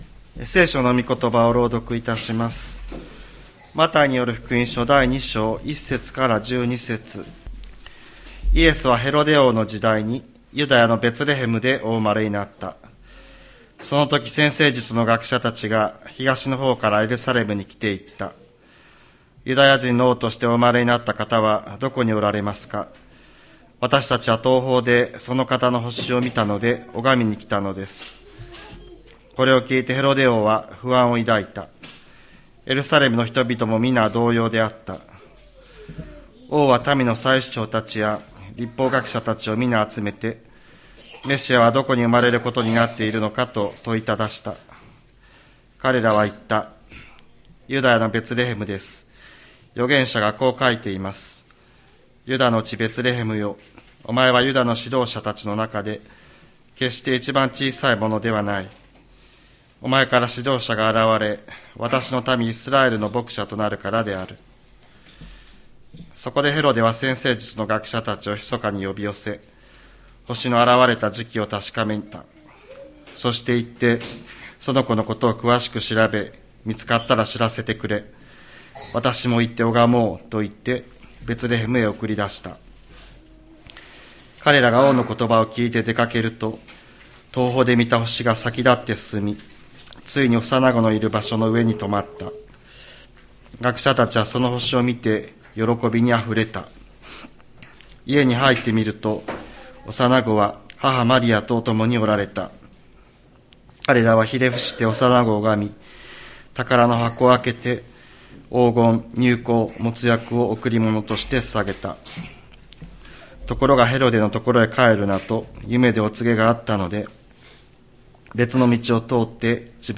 千里山教会 2024年12月22日の礼拝メッセージ。